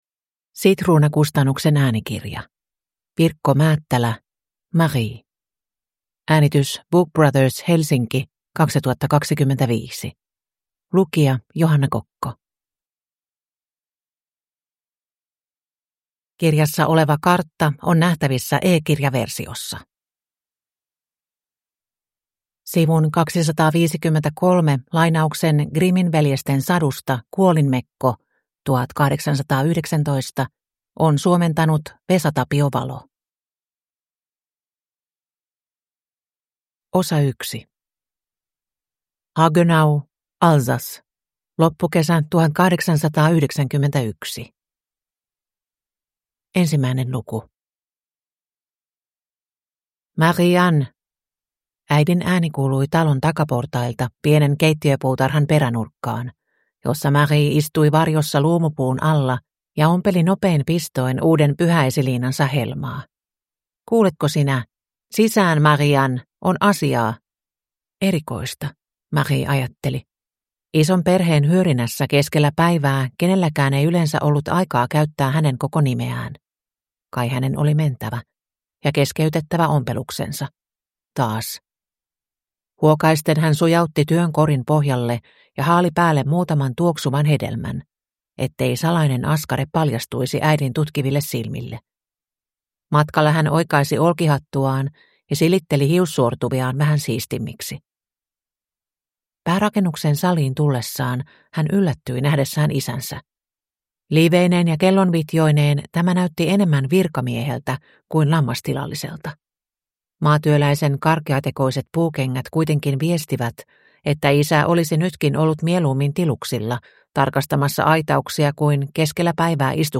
Marie – Ljudbok